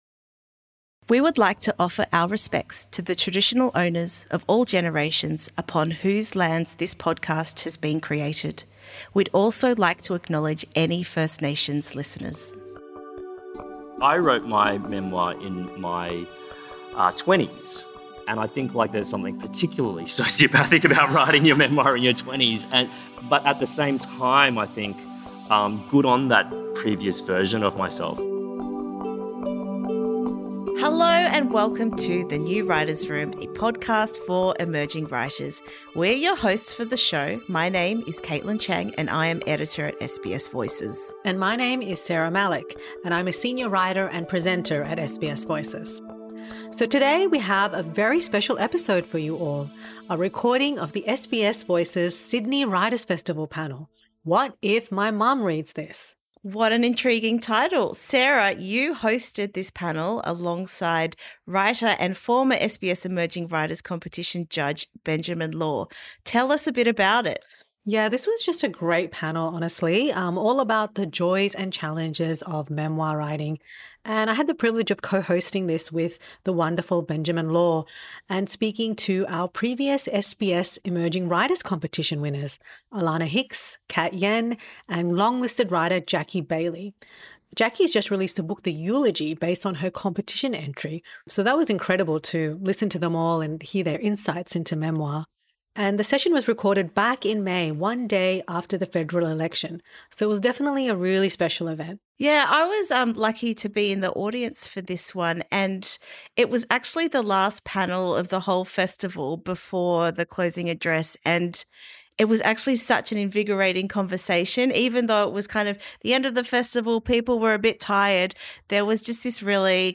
Listen to our Sydney Writers' Festival panel, 'What If My Mum Reads This?'
In a special episode of The New Writer's Room, listen to an exclusive stream of our memoir panel from Sydney Writers' Festival.